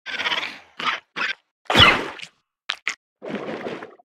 File:Sfx creature babypenguin hold sneeze under 01.ogg - Subnautica Wiki
Sfx_creature_babypenguin_hold_sneeze_under_01.ogg